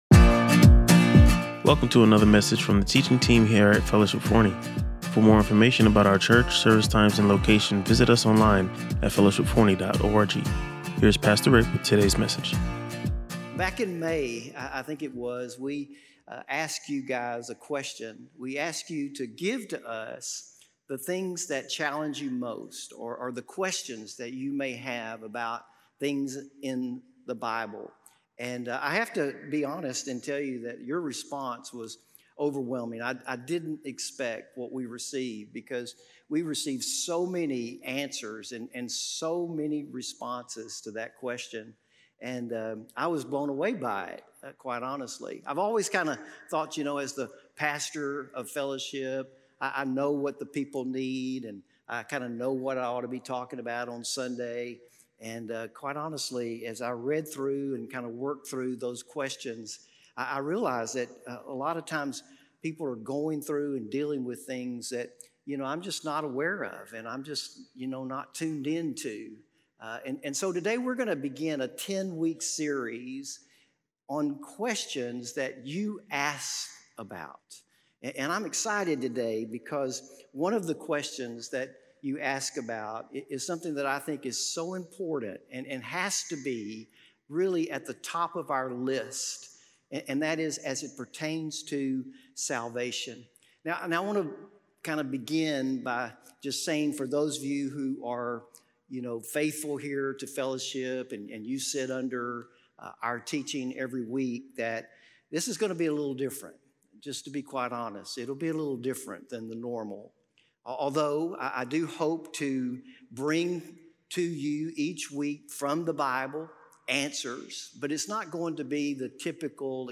Listen to or watch the full sermon to explore these essential spiritual truths.